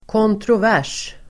Uttal: [kåntråv'är_s:]